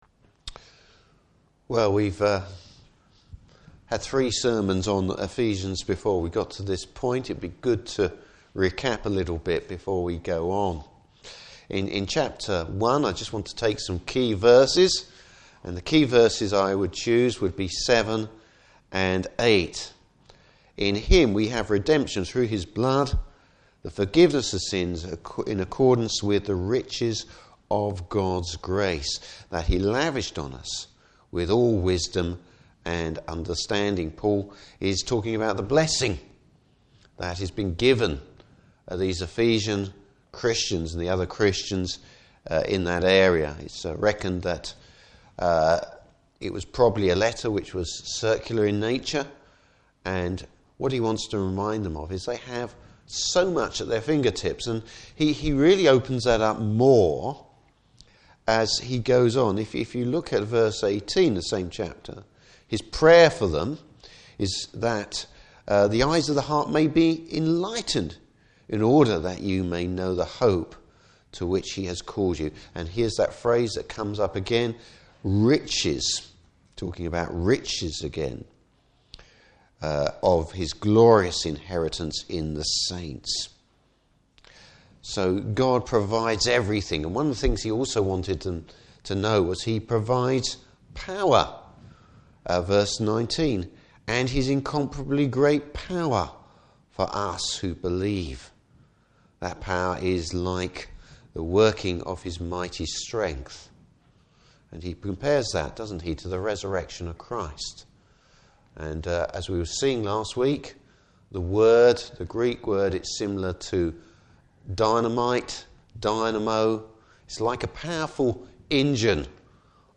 Service Type: Morning Service God’s grace not our works are what saves us.